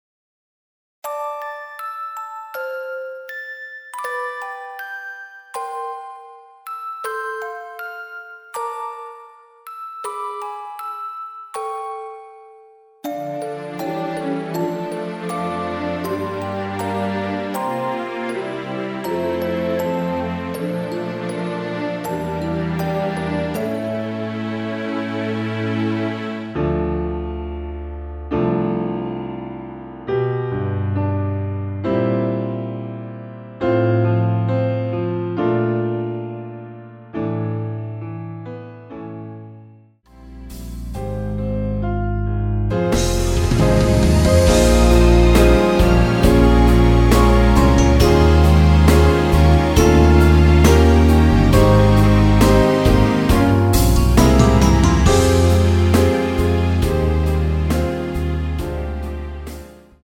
Dm
앞부분30초, 뒷부분30초씩 편집해서 올려 드리고 있습니다.